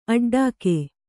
♪ aḍḍāke